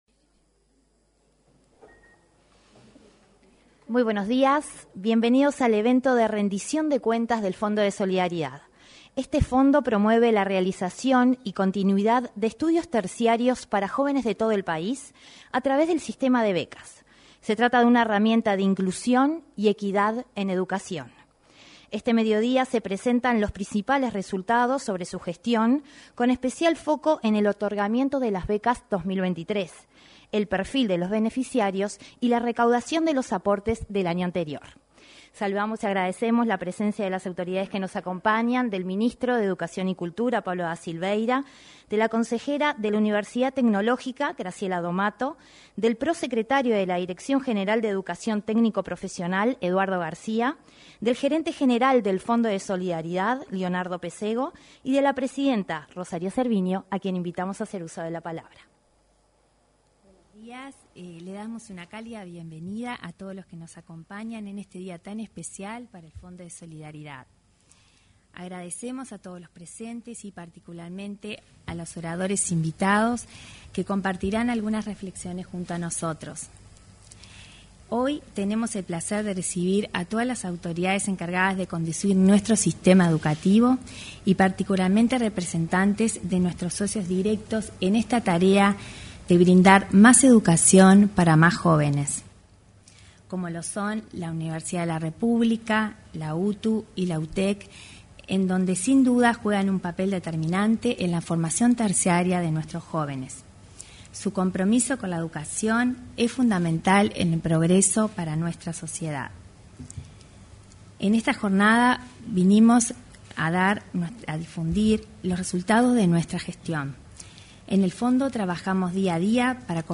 Este lunes 14, se realizó en el salón de actos de la Torre Ejecutiva, el evento Impacto de las Becas y Rendición de Cuentas del Fondo de Solidaridad.